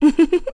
Laudia-Vox_Happy1_kr.wav